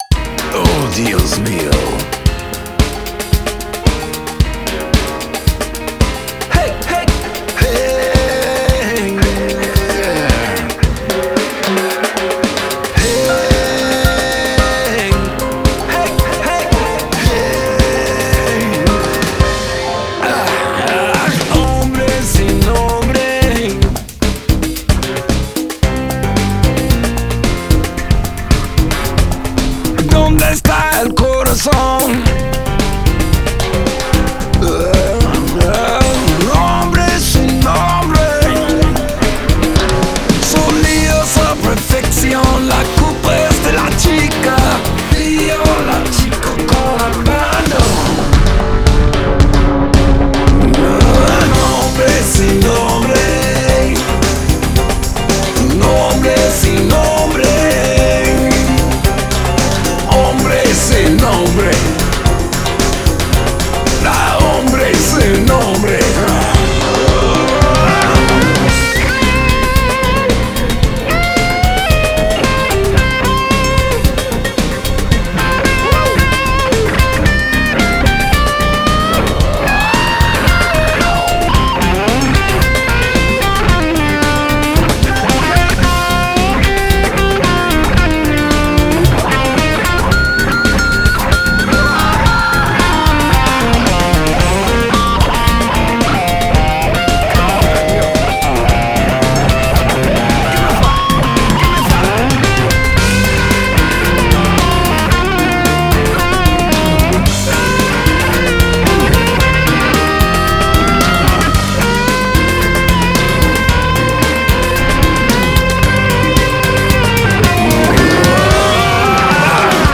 Жанр: Blues Rock